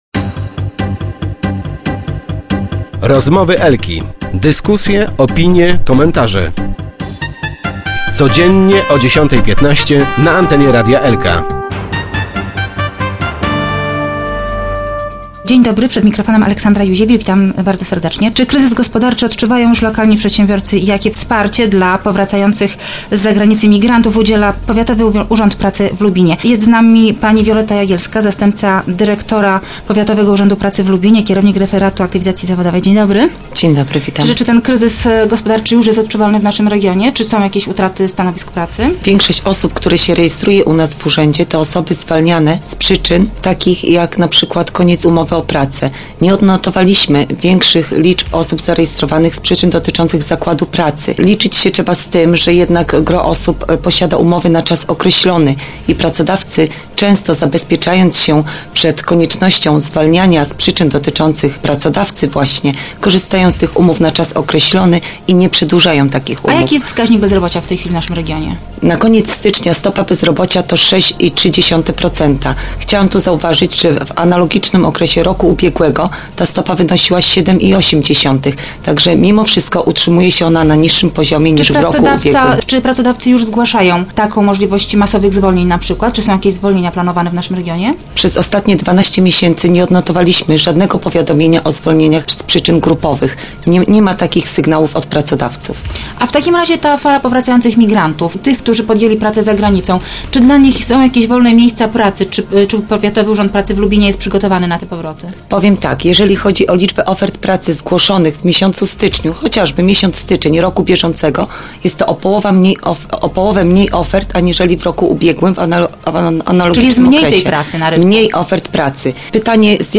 Start arrow Rozmowy Elki